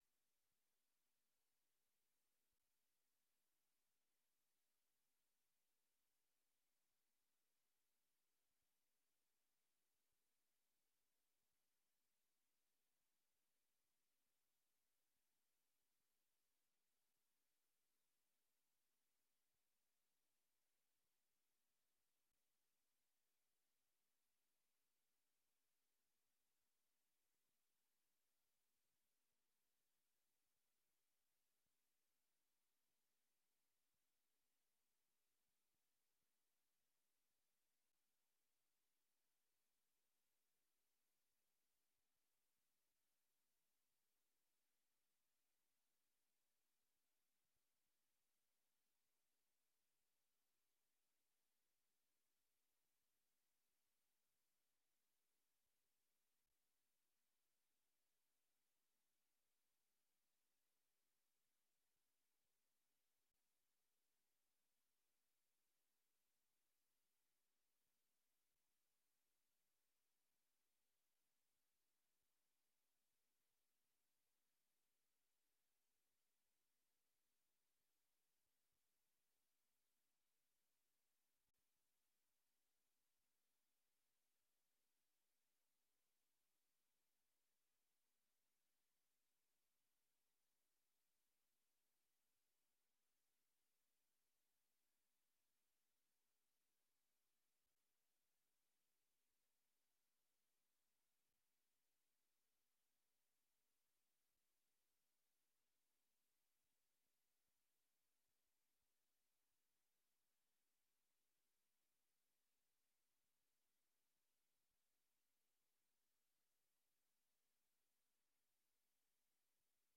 Oordeelsvormende vergadering 14 december 2023 19:30:00, Gemeente Dronten
Locatie: Raadzaal